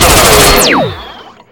rifle1.ogg